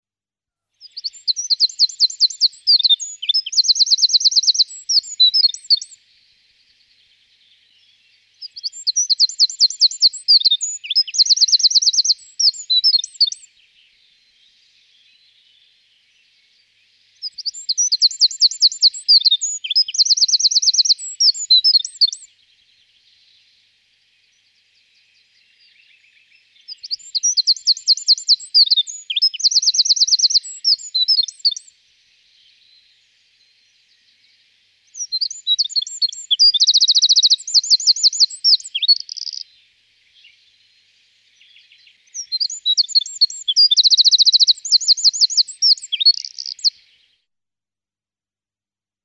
Troglodyte mignon
Chant :
Le chant du troglodyte mignon est très perçant et puissant pour un oiseau aussi petit. Le chant est un mélange de trilles et de bavardages crépitants, finissant par un autre trille rapide et sec.
Le cri est un " churr " bas et discordant, et aussi un " tek " perçant et souvent répété.
28Wren.mp3